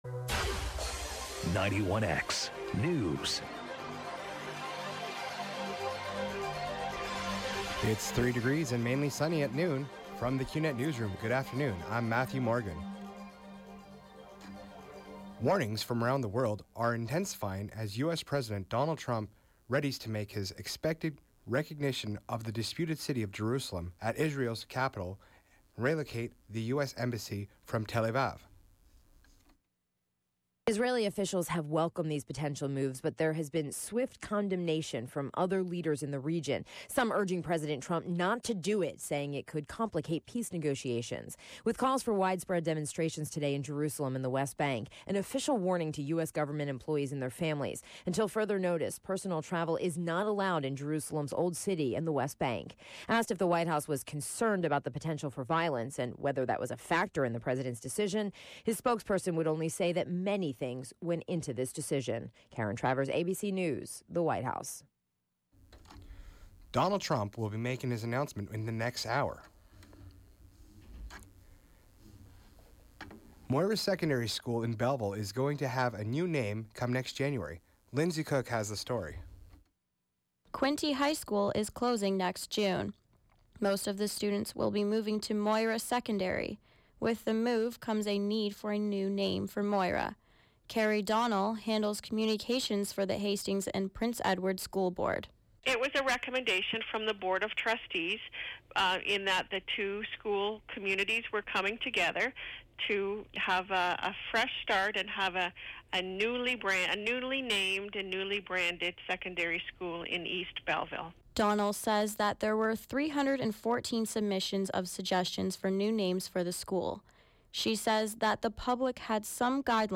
91X Newscast: Wednesday, Dec. 6, 2017, noon.